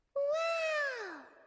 Anime Wow
wow_17.mp3